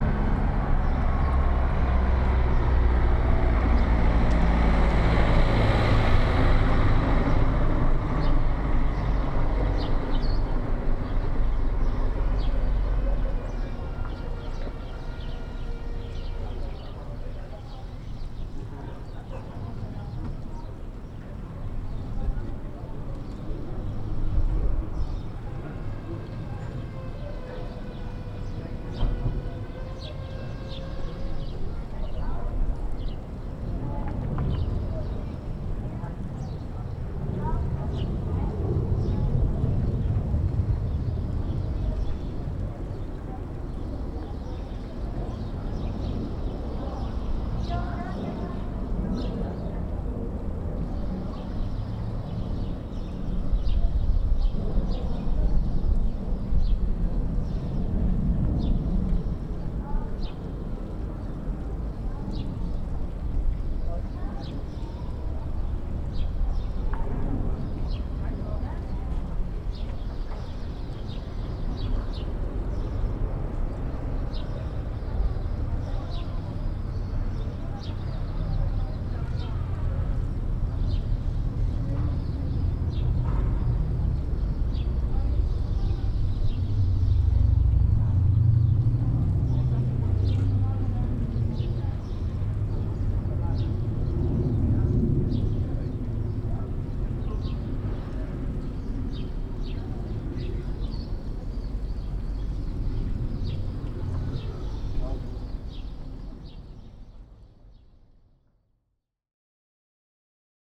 Teguise_Marktplatz_1.mp3